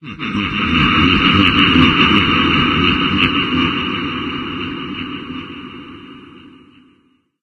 controller_attack_1.ogg